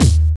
Kick (Hoe Cakes).wav